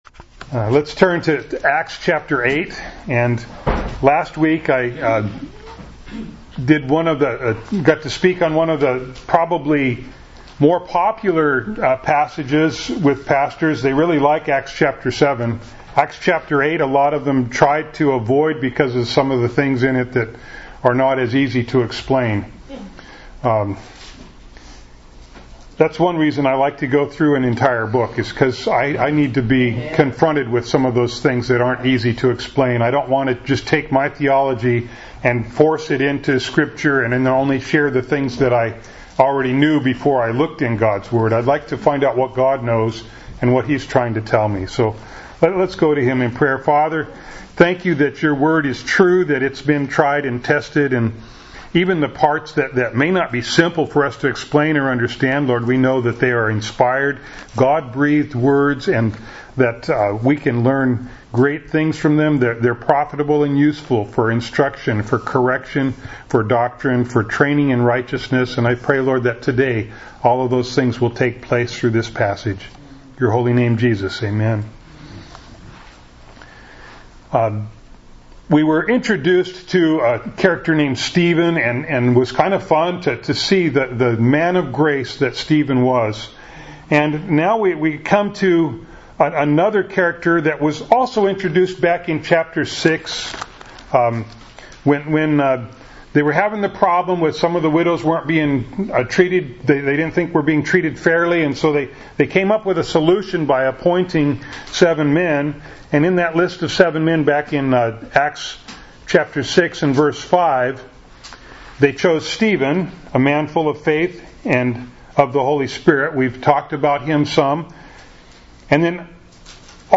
Acts 8:1b-25 Service Type: Sunday Morning Bible Text